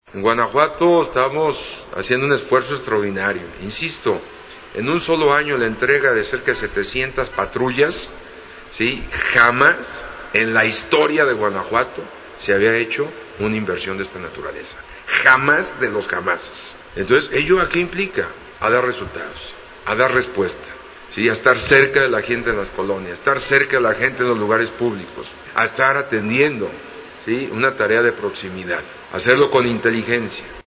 audiogobernador-patrullas.mp3